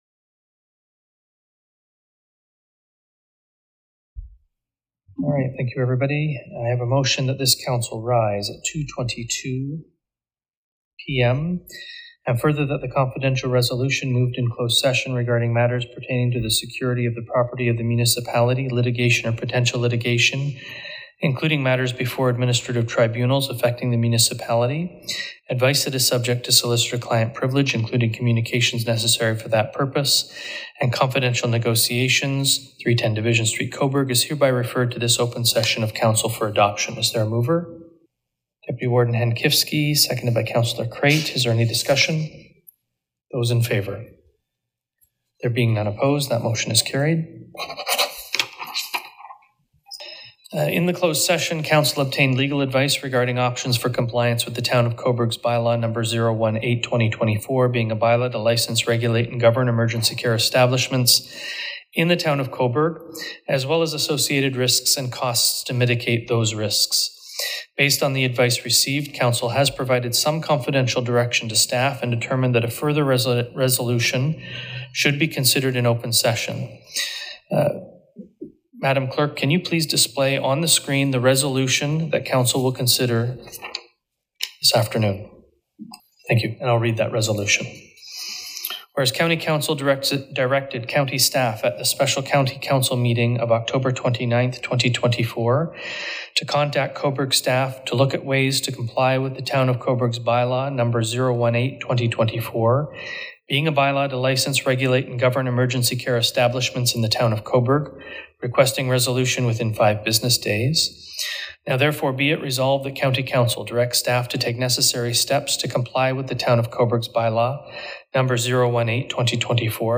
This In Their Words shares the open session where council finalized its direction.
The open session was short, just over five minutes.